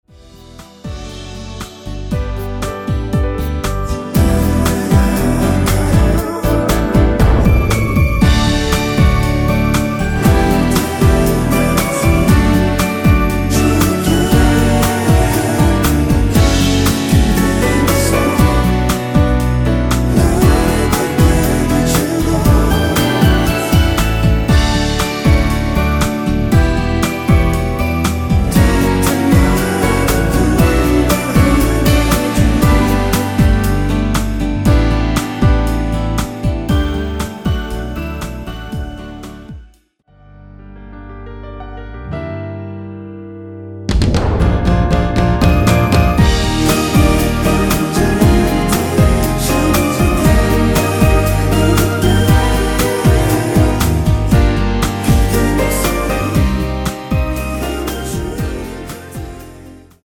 원키에서(-1)내린 코러스 포함된 MR 입니다.(미리듣기 참조)
앞부분30초, 뒷부분30초씩 편집해서 올려 드리고 있습니다.